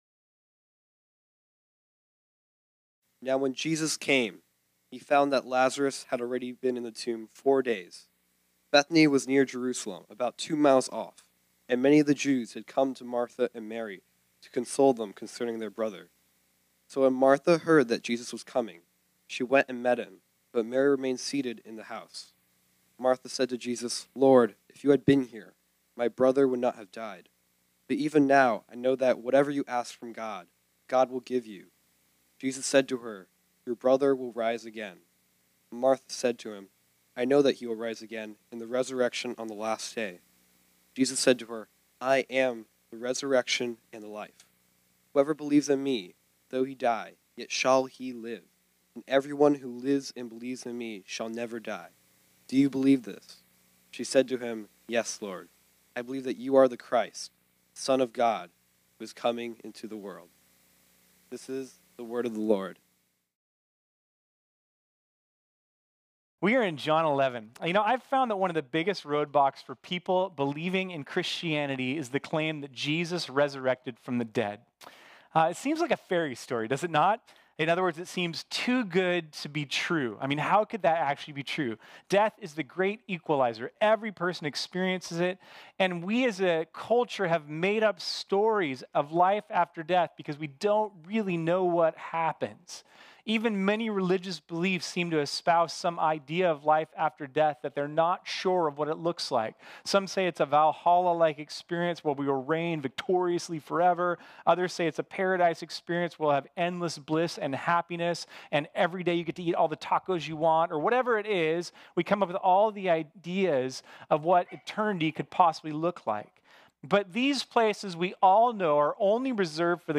This sermon was originally preached on Sunday, February 16, 2020.